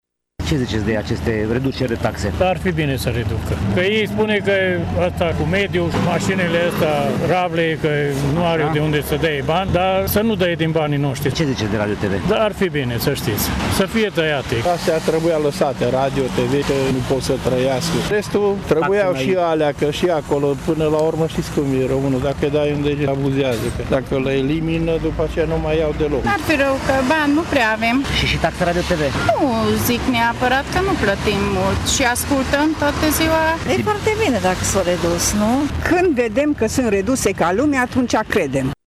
În egală măsură, cetățeni obișnuiți sau oamenii politici, tîrgumureșenii cu care am stat de vorbă au fost de acord că birocrația românească este exagerată, iar inflația de taxe și impozite este sufocantă.